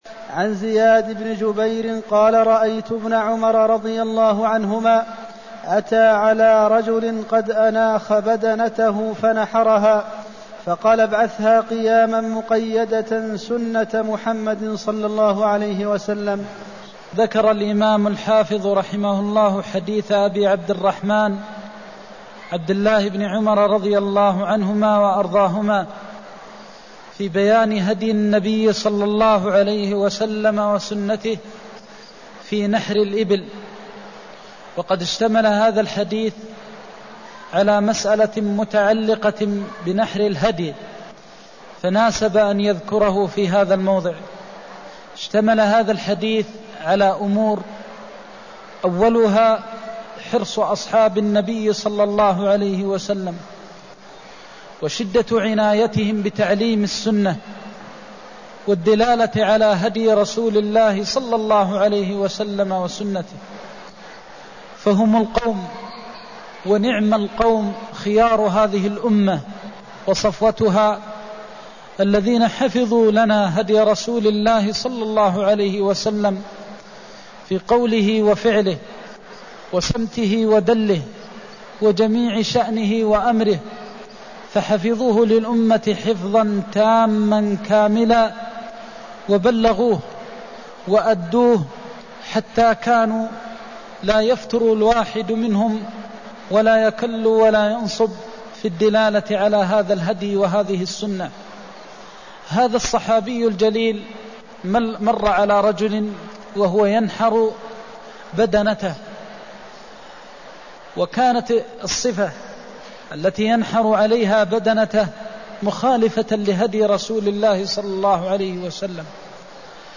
المكان: المسجد النبوي الشيخ: فضيلة الشيخ د. محمد بن محمد المختار فضيلة الشيخ د. محمد بن محمد المختار ابعثها قياماً مقيدة سنة محمد (228)